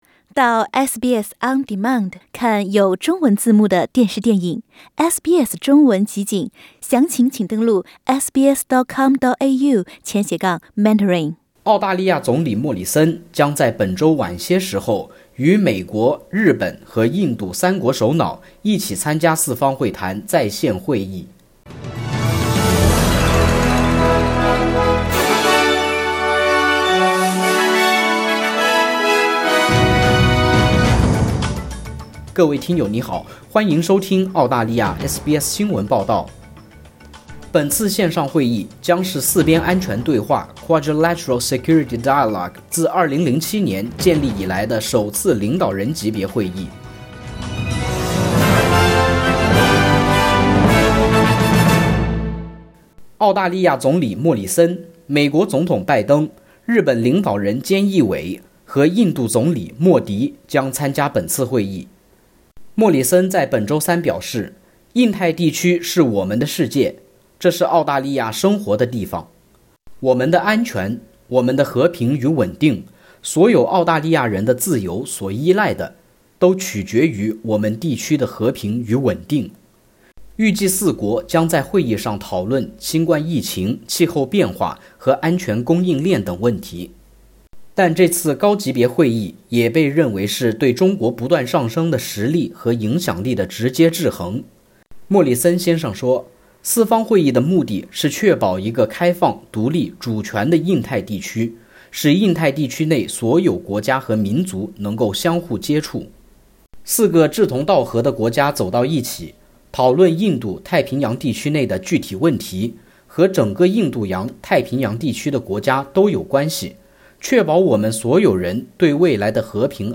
美日印澳領導人將在澳洲東部時間週六（3月13日）舉行在線會議。(點擊上圖收聽報道)